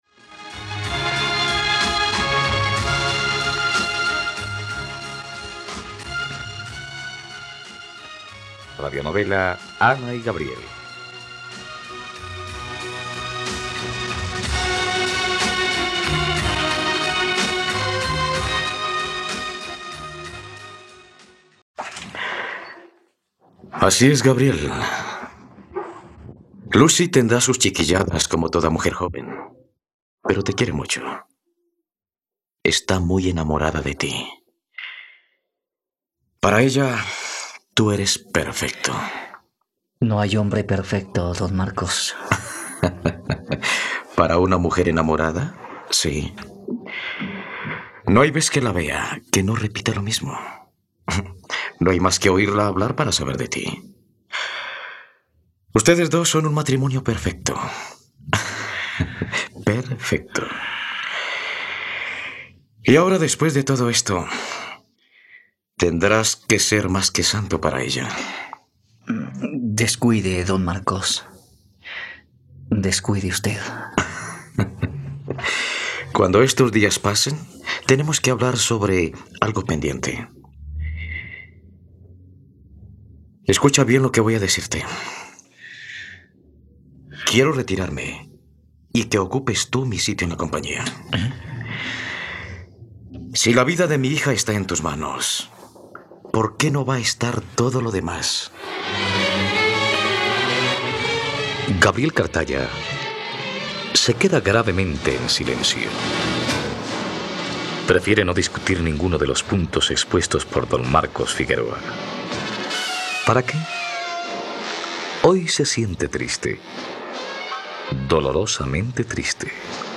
..Radionovela. Escucha ahora el capítulo 53 de la historia de amor de Ana y Gabriel en la plataforma de streaming de los colombianos: RTVCPlay.